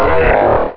Cri de Hariyama dans Pokémon Rubis et Saphir.